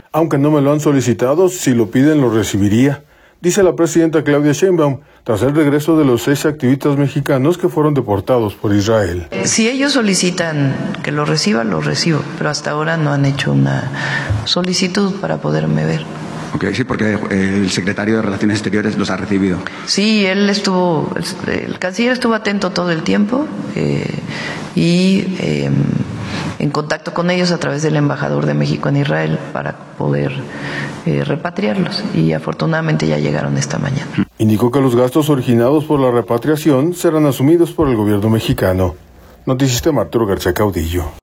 Aunque no me lo han solicitado, si lo piden los recibiría, dice la presidenta Claudia Sheinbaum, tras el regreso de los seis activistas mexicanos que fueron deportados por Israel.